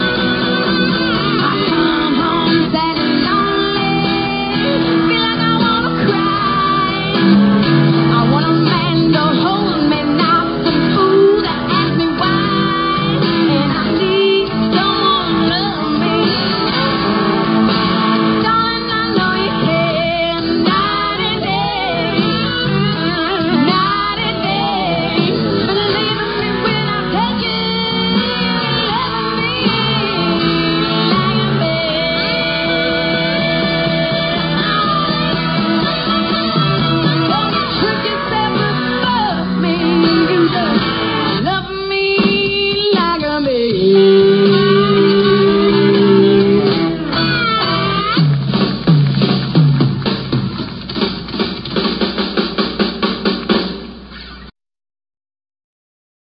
CONCERT CLIPS